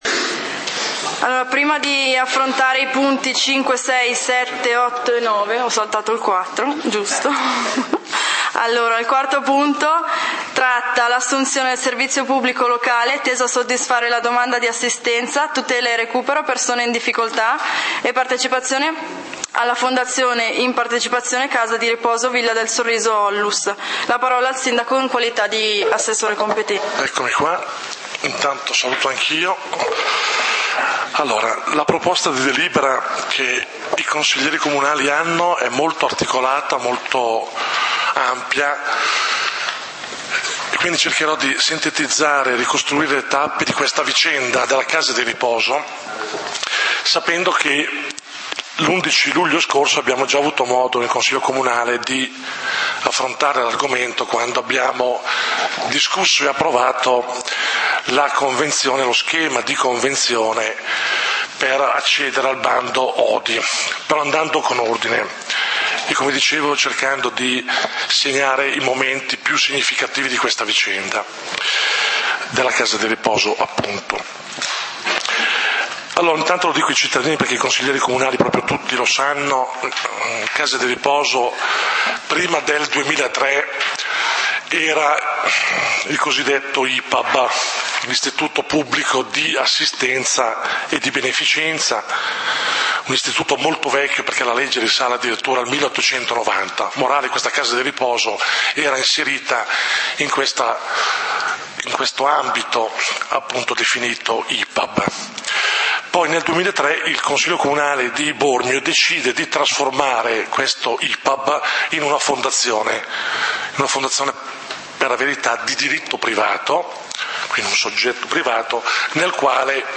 Punti del consiglio comunale di Valdidentro del 30 Ottobre 2012